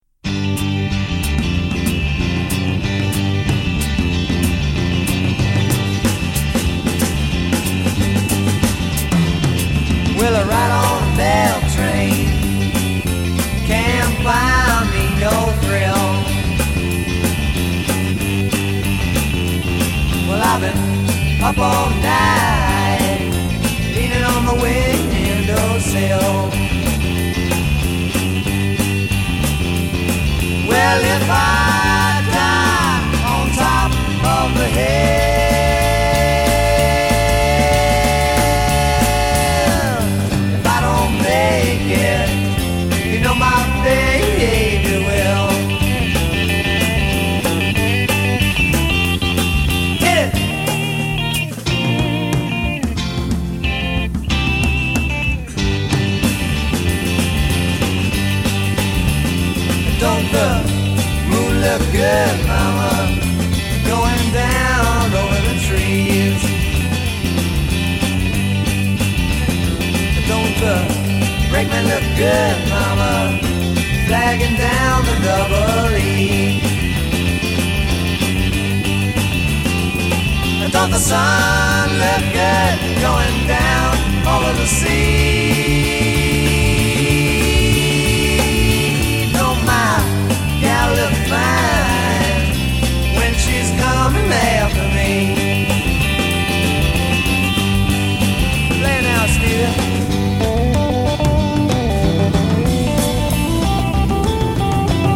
batterie